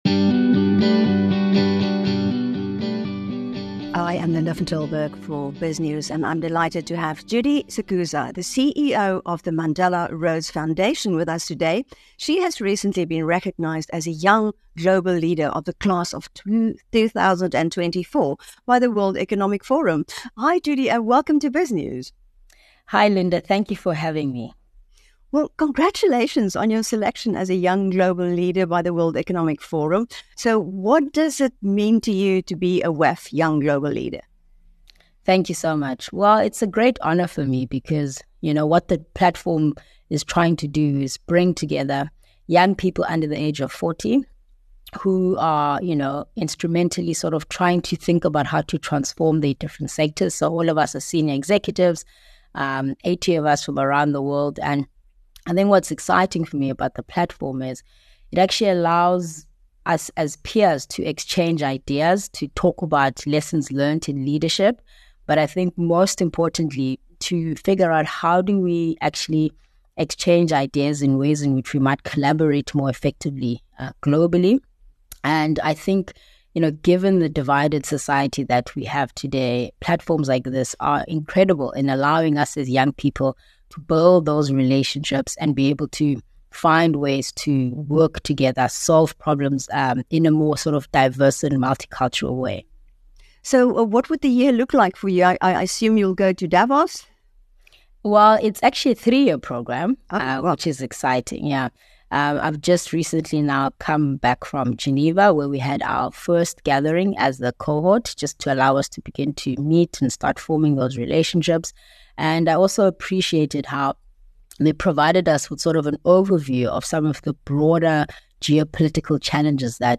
In an interview with Biznews